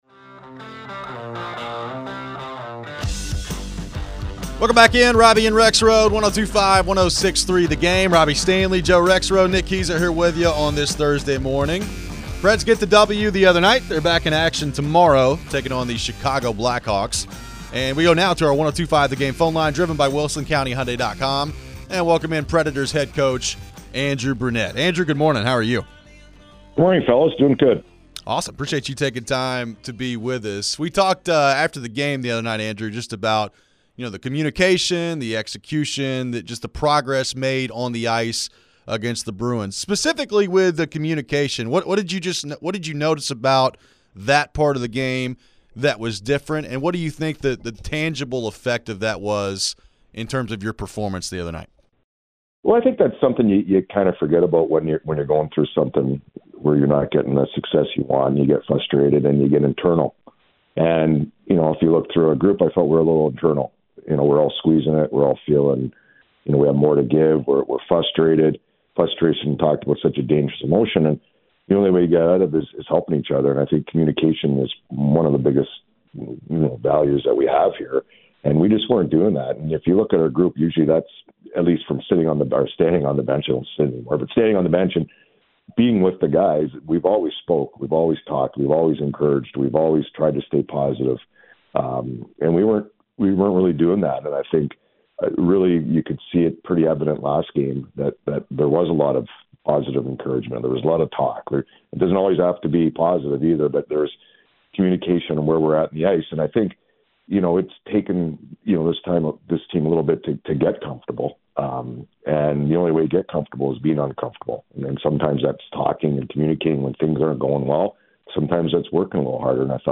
Andrew Brunette interview (10-24-24)
Nashville Predators head coach Andrew Brunette joined the show after seeing his team win No. 1 this week. How does he feel about the powerplay, getting better as a team, and battling discomfort?